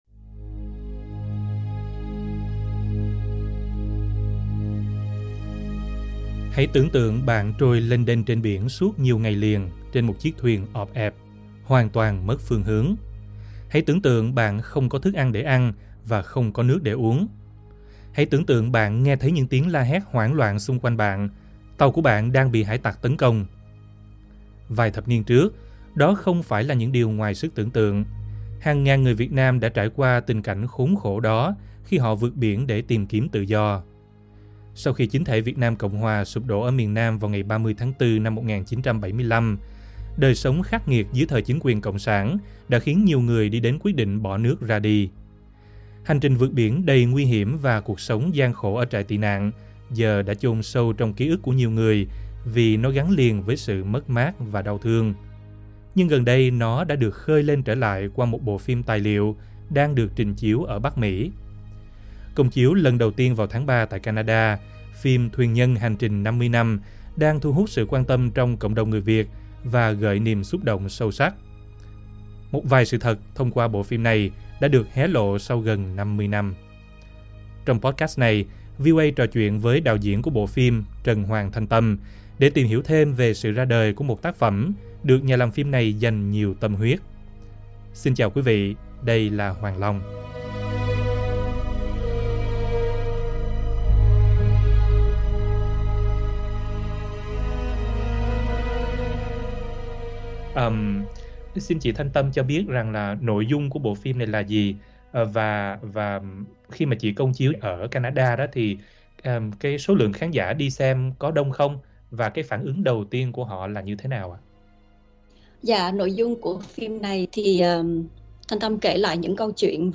trò chuyện với VOA